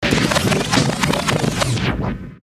Âm thanh Tua lại, Quay lại nhịp độ nhanh
Thể loại: Hiệu ứng âm thanh
Description: Âm thanh Tua lại, Quay lại nhịp độ nhanh là tiếng tua nhanh xẹt xẹt mô tả một thước phim, một hành động được tua lại với tốc độ cực kỳ nhanh, trong một thời gian ngắn nhưng tua lại biết bao sự việc, hành động, âm thanh lẹt xẹt khi tua lại những sự việc đã xảy ra rất lâu chỉ trong vài giây.
am-thanh-tua-lai-quay-lai-nhip-do-nhanh-www_tiengdong_com.mp3